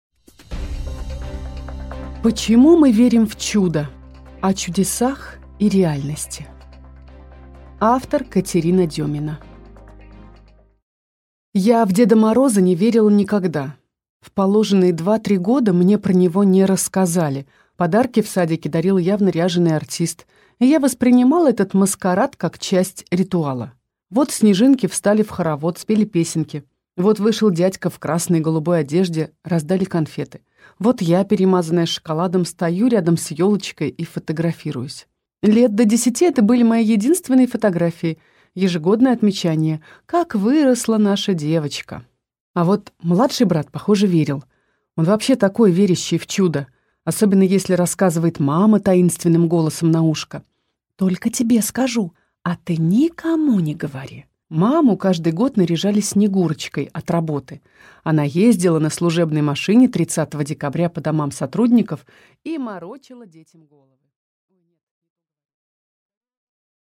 Аудиокнига Почему мы верим в чудо?
Прослушать и бесплатно скачать фрагмент аудиокниги